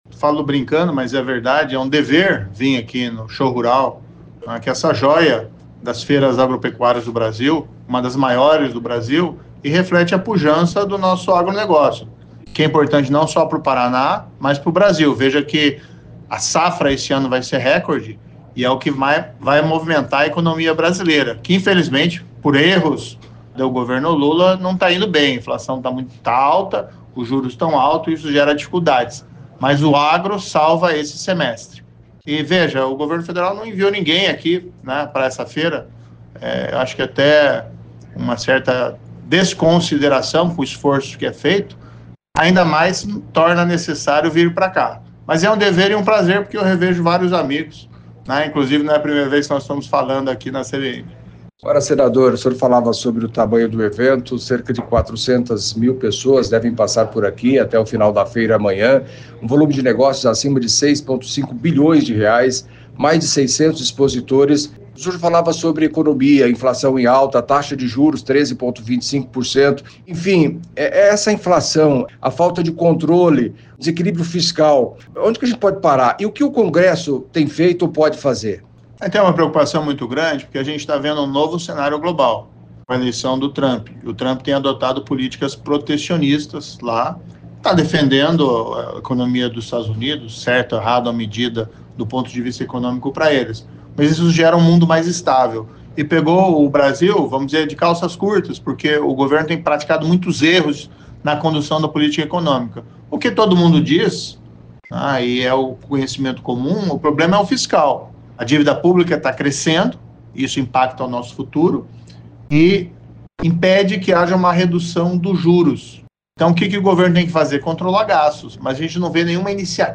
Em entrevista à CBN, no estúdio montado no Show Rural, em Cascavel, no Oeste do Paraná, o senador paranaense Sérgio Moro, do União Brasil, destacou a importância do agro; voltou a criticar duramente o governo Lula e, entre outros assuntos, falou também das eleições de 2026.